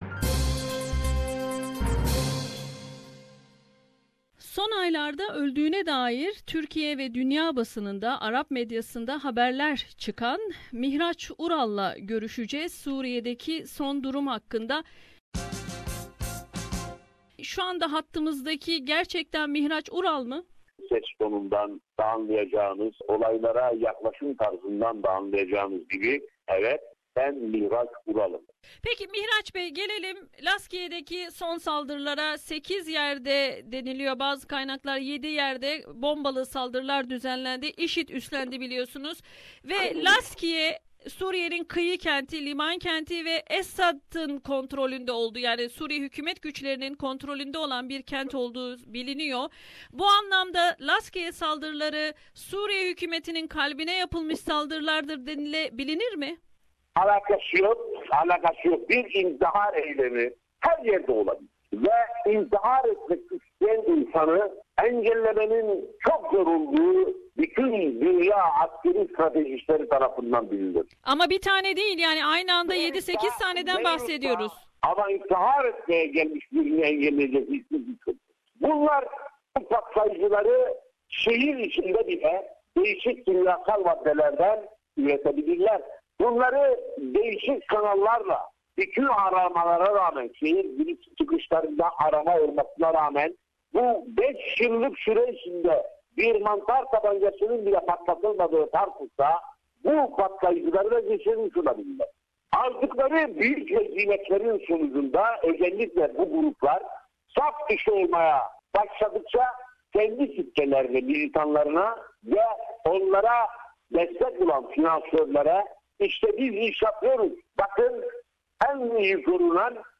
Bir çok basın yayın organında öldürüldüğüne dair haberler yayınlanan ve Suriye'de, Suriye Hükümeti saflarında, ‘Mukavveme Suriye’ adlı örgütün başında savaşan, Türkiye Cumhuriyeti'nin arananlar listesinde bulunan Mihraç Ural SBS Radyosu'na verdiği özel söyleşide, hayatta olduğunu söyledi ve Esad yönetiminin en güçlü olduğu kentlerden Lazkiye ve Tartus'ta düzenlenen IŞİD saldırıları, Rusya’nın Suriye’ye verdiği destek ve Suriyeli Kürt’lerin, diğer güçlerle birlikte Rakka’ya doğru ilerlemesi hakkında değerlendirmelerde bulundu.